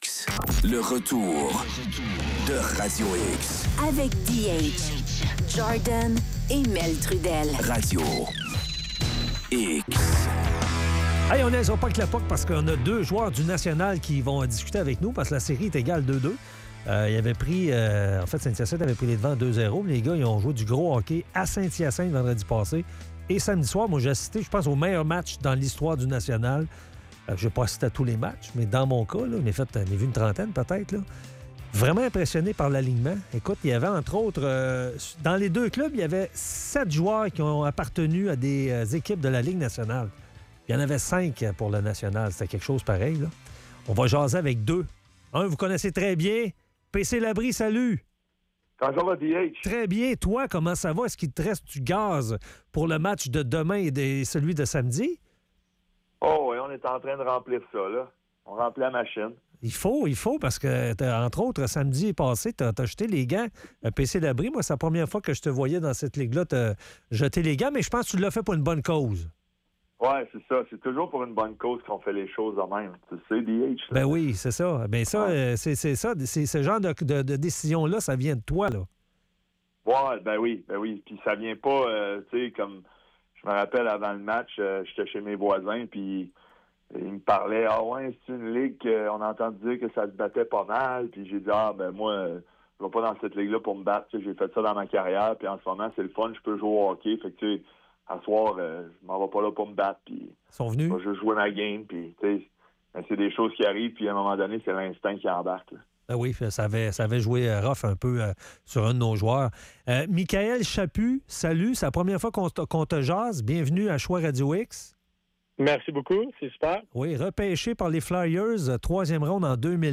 Entrevue